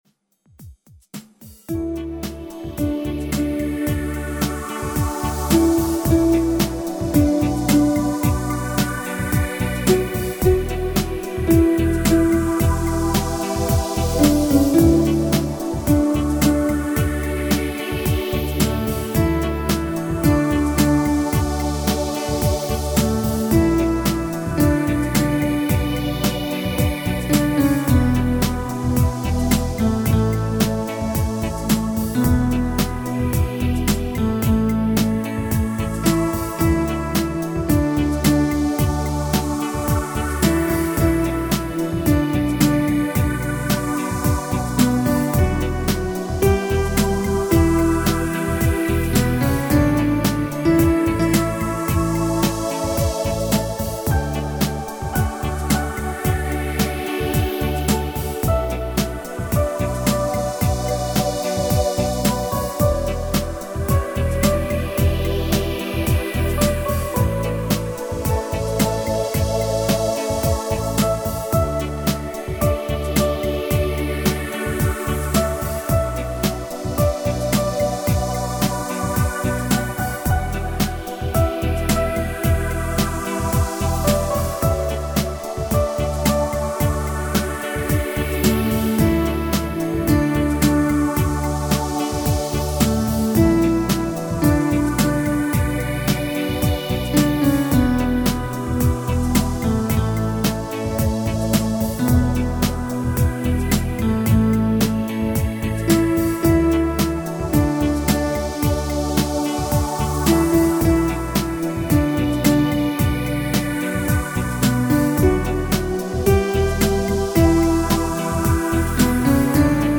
Минус